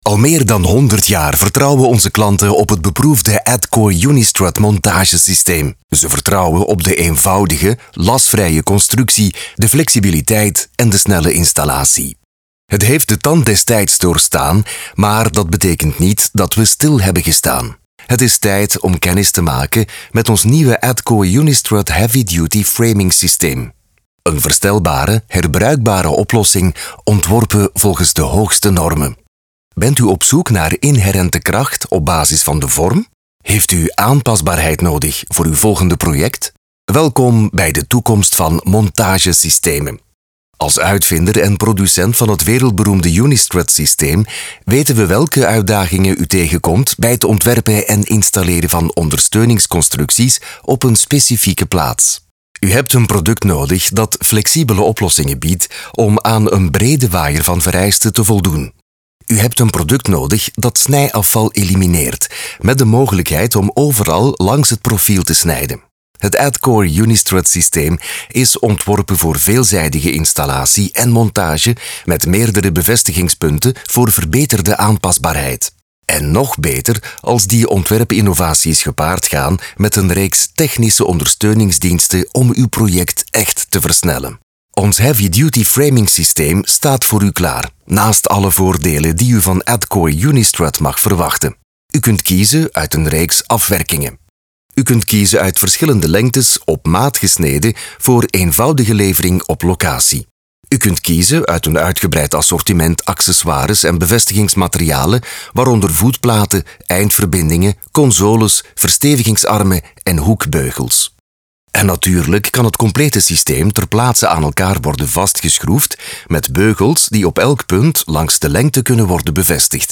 Warm, Tief, Zuverlässig, Erwachsene, Zugänglich
Unternehmensvideo